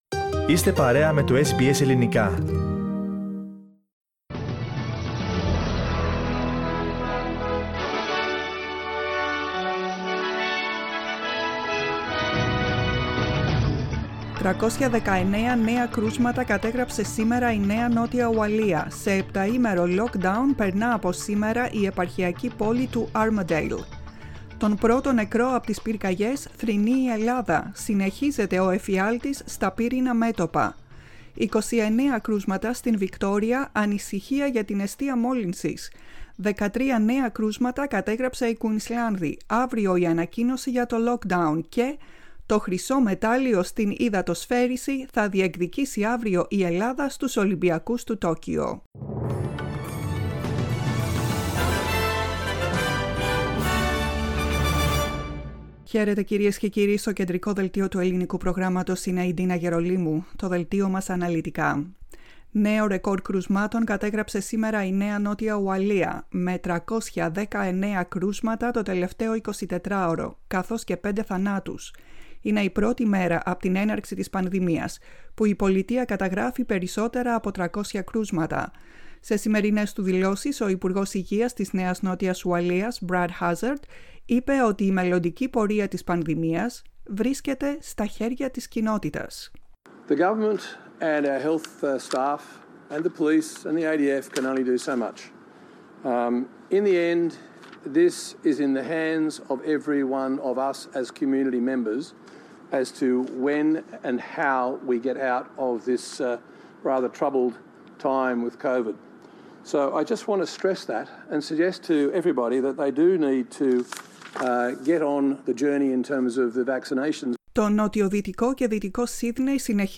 Listen to the main bulletin of the day from the Greek Language Program.